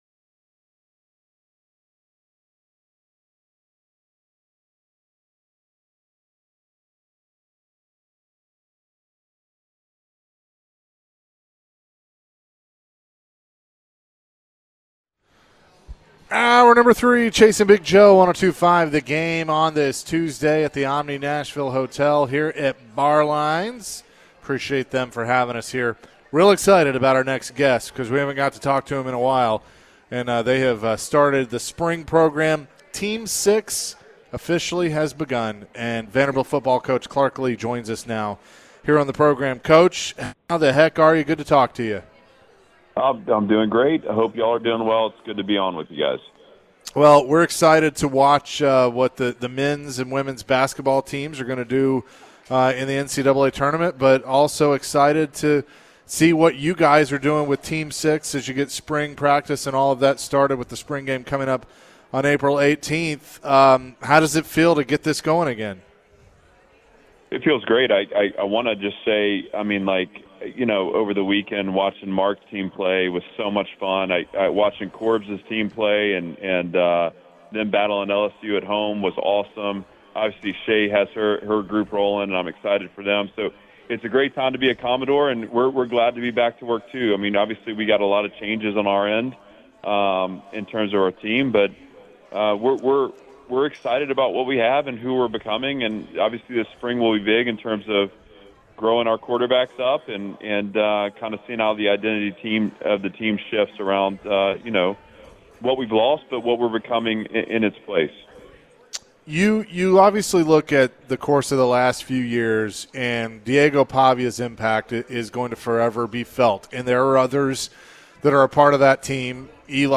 Vanderbilt head coach Clark Lea joined the show and shared his thoughts about his football team heading into spring practice. Clark commented on Diego Pavia's case in the NFL and why a team should draft him.